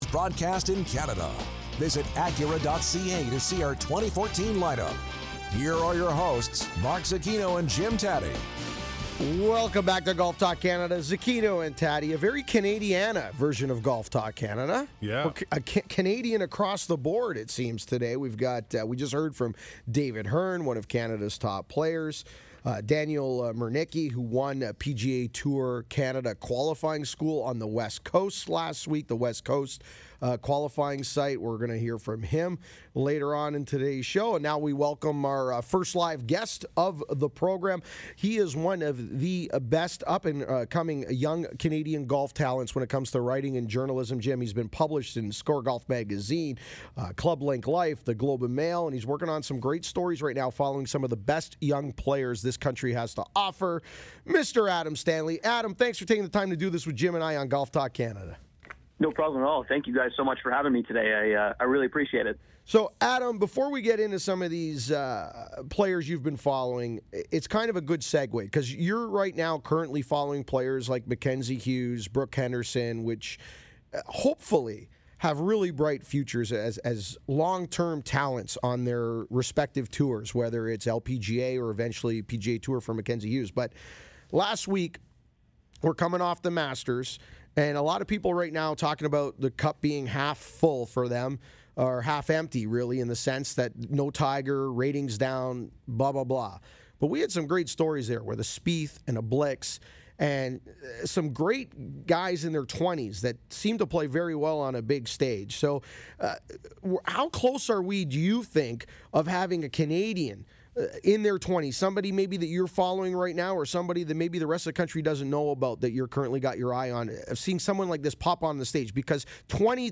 The whole show is available here, but I’ve edited my segment and you can listen by clicking on the icon below. I talk about Brooke Henderson, Mackenzie Hughes, the next generation of Canadian golfers and a little bit about myself.